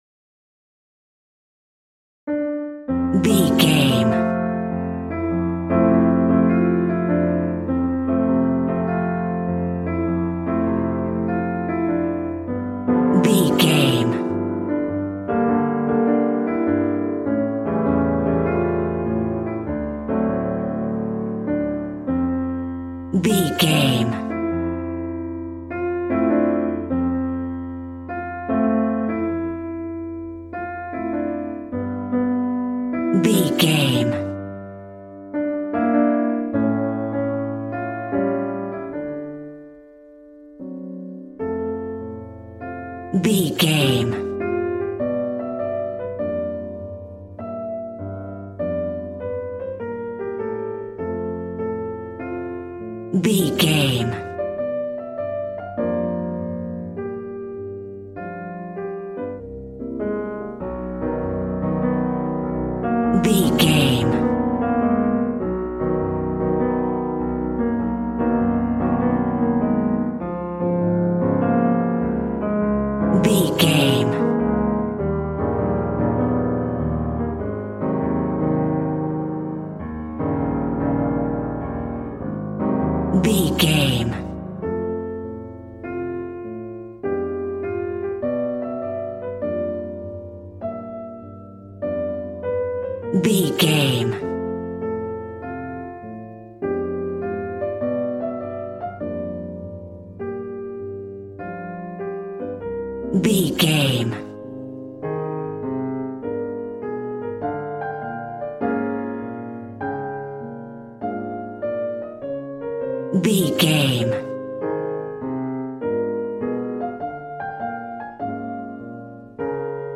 Ionian/Major
piano
drums